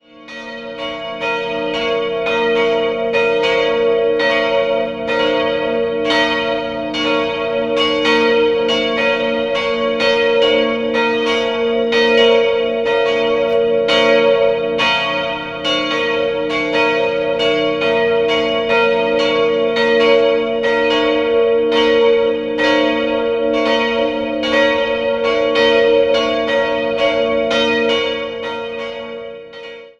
Die heutige Kirche wurde Ende des 18. Jahrhunderts errichtet und besitzt im Inneren drei einfache barocke Altäre. 2-stimmiges Geläut: b'-des'' Die größere Glocke wurde 1956 von Friedrich Wilhelm Schilling in Heidelberg gegossen, die kleinere 1798 von Joseph Stapf in Eichstätt.